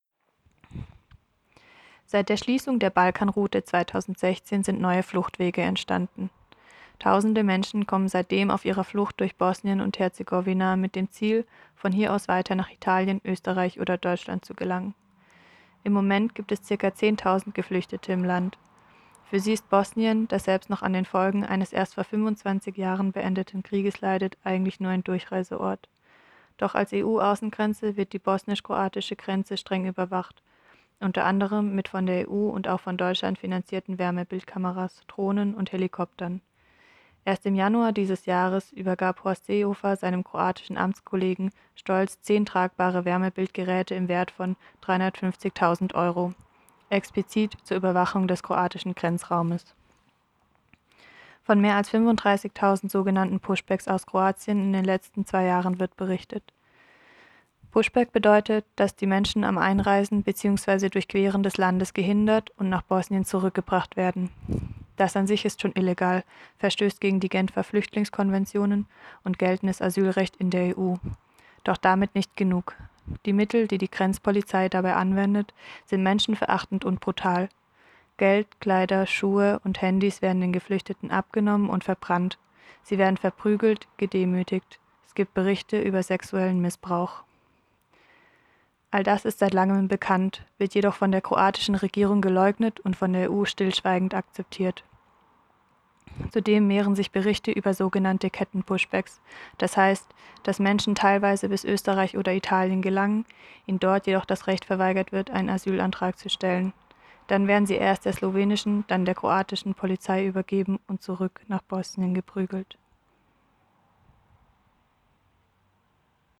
Um den Finger auf die derzeitige Situation von Schutzsuchenden in Europa zu legen, gab es zudem Berichte zur Situation auf Lesvos und der Situation an der bosnisch-kroatischen Grenze.